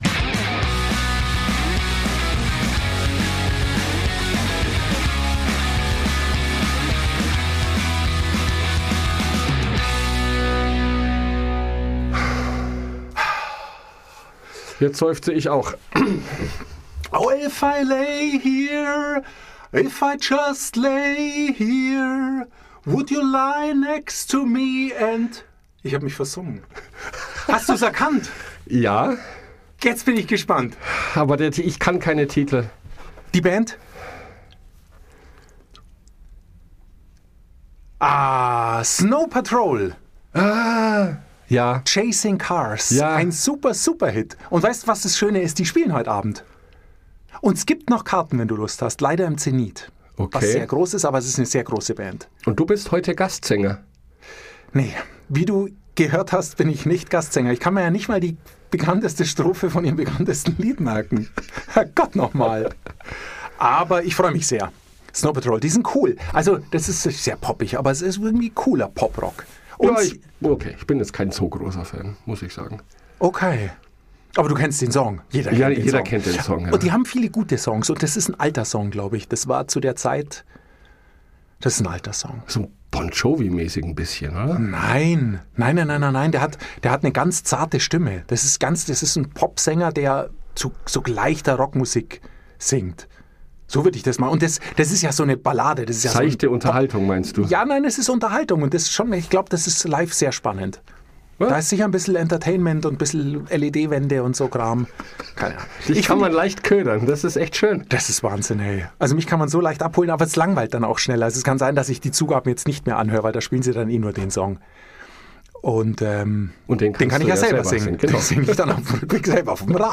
Und hat sich prompt versungen.